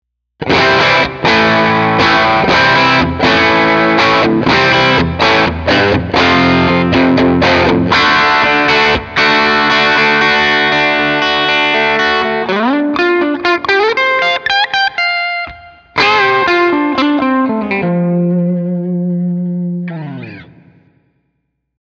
Tutte le clip audio sono state registrate con testata a valvole artigianale da 15W ispirata al Cornell Romany e cassa 1×12 equipaggiata con altoparlante Celestion A-Type impostato su un suono estremamente clean.
Chitarra: Fender Stratocaster (pickup al ponte)
Turbo: OFF
Engine: 7/10